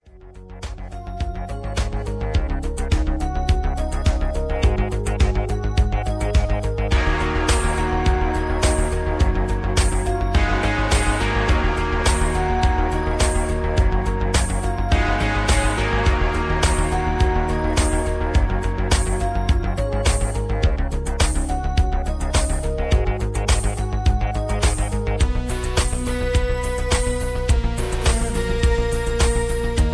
(Key-Ebm) Karaoke MP3 Backing Tracks
Just Plain & Simply "GREAT MUSIC" (No Lyrics).